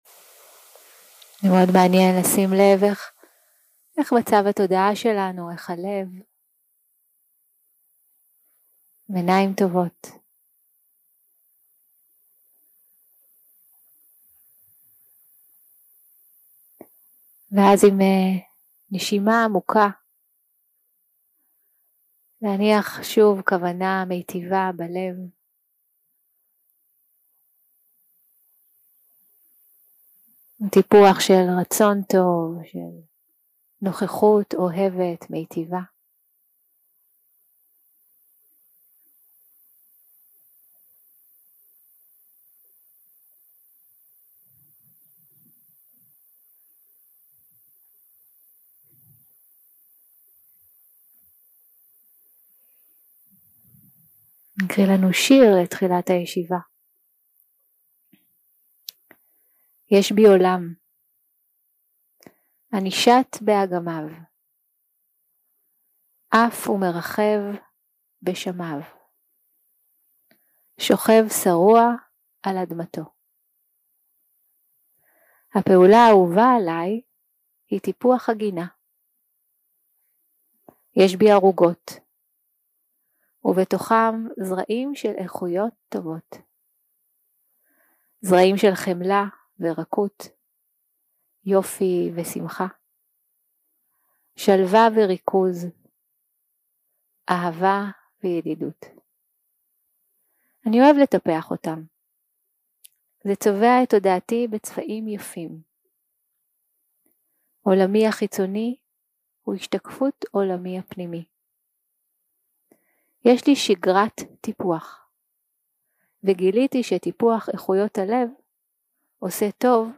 יום 4 – הקלטה 8 – בוקר – מדיטציה מונחית – להתאמן מעומק הלב 0:00 0:00 סוג ההקלטה: Dharma type: Guided meditation שפת ההקלטה: Dharma talk language: Hebrew